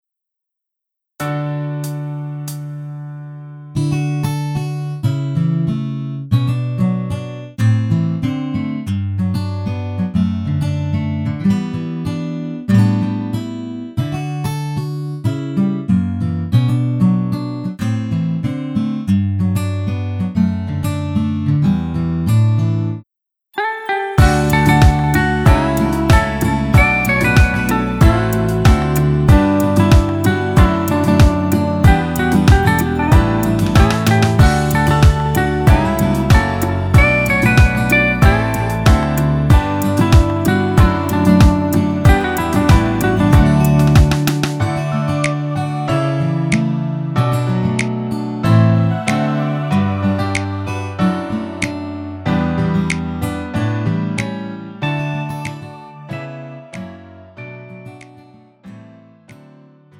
음정 -1키 3:11
장르 가요 구분 Pro MR
Pro MR은 공연, 축가, 전문 커버 등에 적합한 고음질 반주입니다.